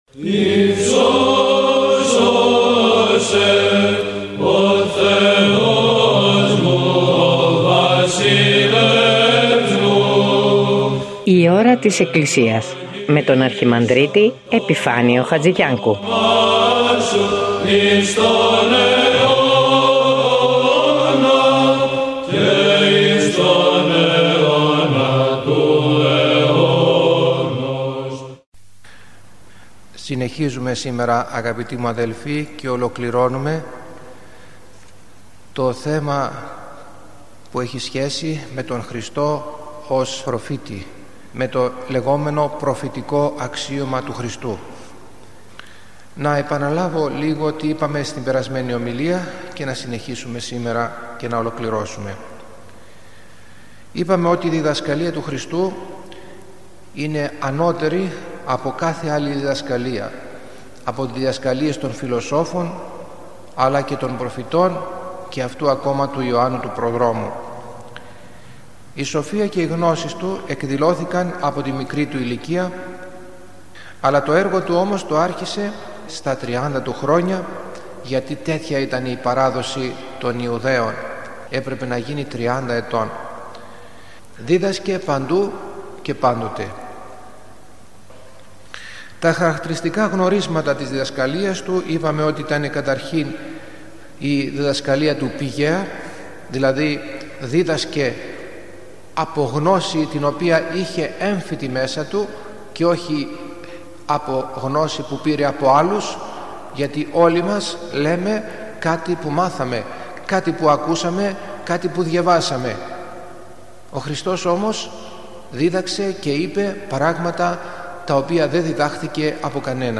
Μια ένθετη εκπομπή που μεταδίδονται ομιλίες